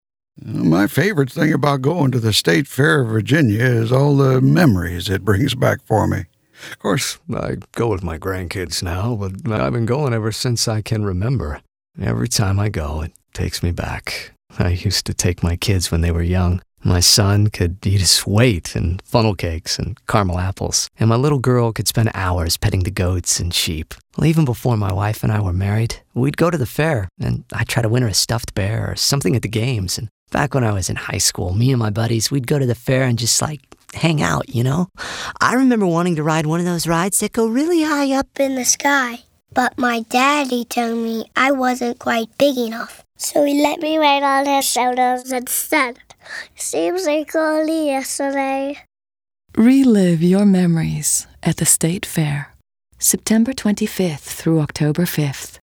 The finalists have been announced in the Radio-Mercury Awards for radio spots.